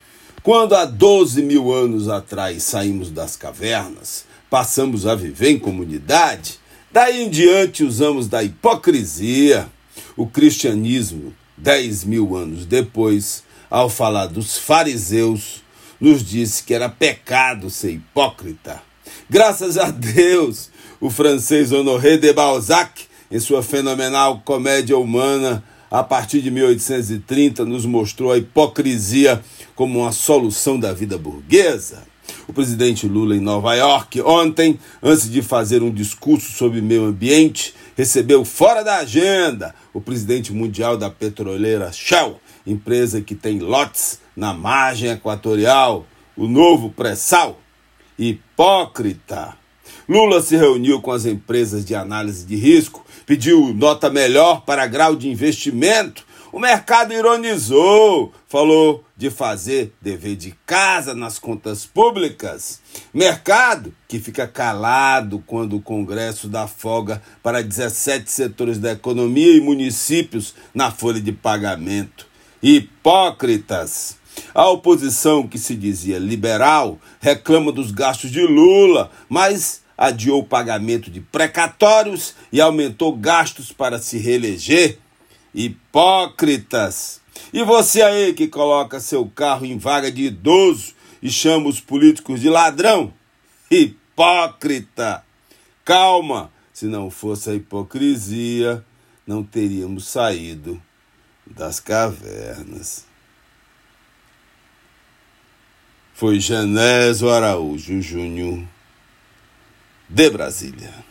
Comentário desta terça-feira